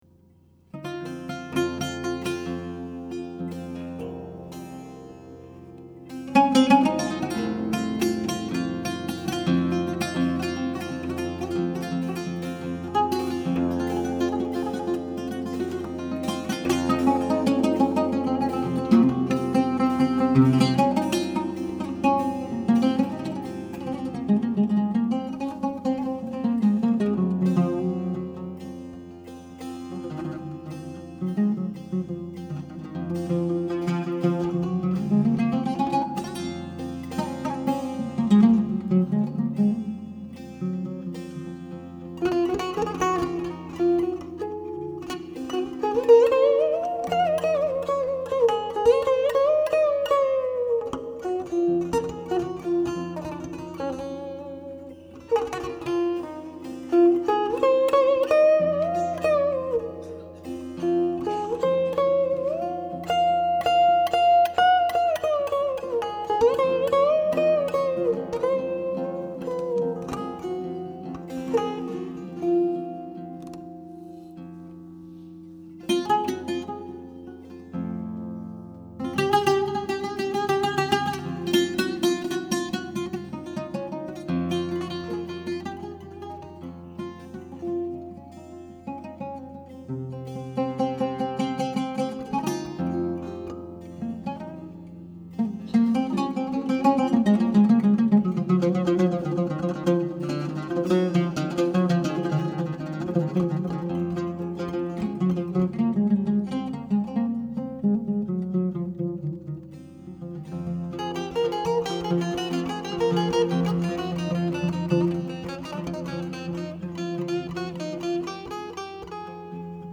sitar.
oud.
tabla.